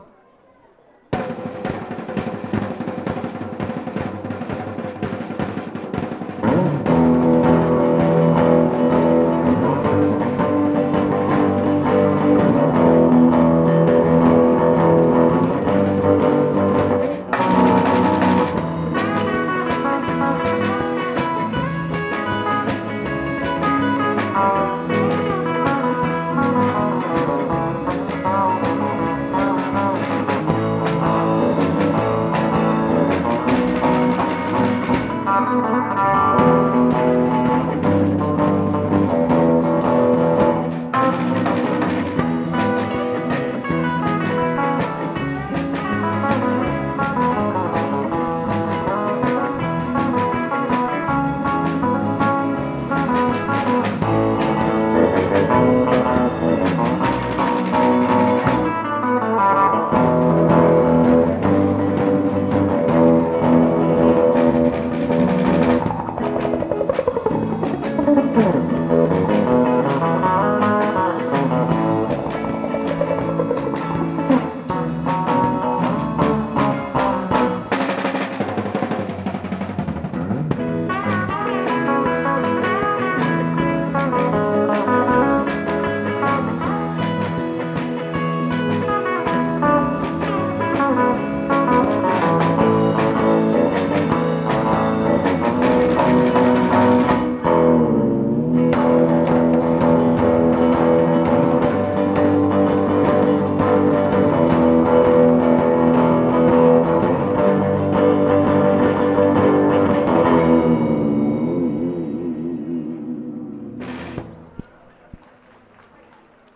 11月12日（土）「キンシ正宗新常盤蔵」にて「秋の蔵出しイベント」に出演しました、
• ライブ